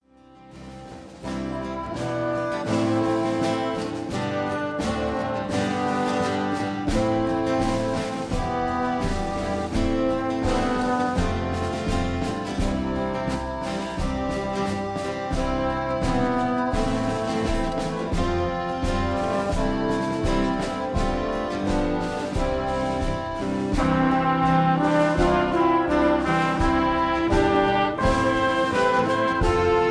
(Key-C)
Tags: backing tracks , irish songs , karaoke , sound tracks